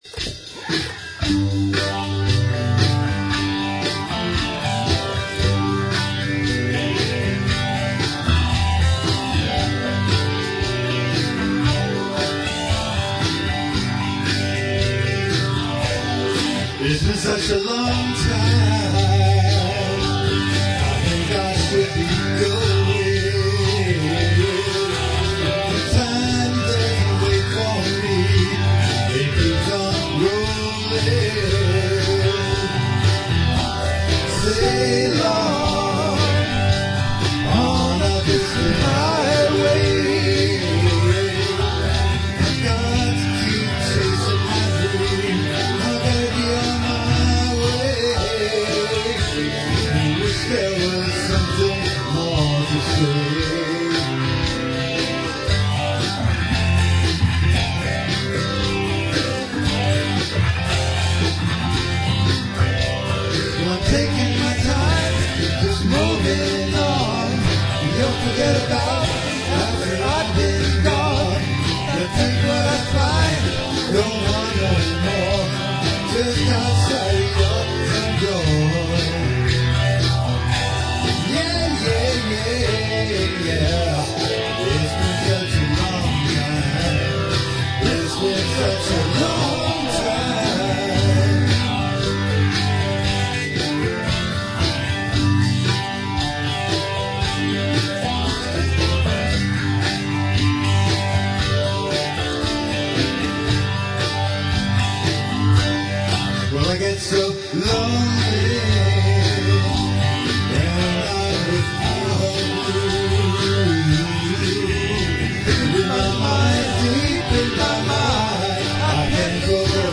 ≈ 120bpm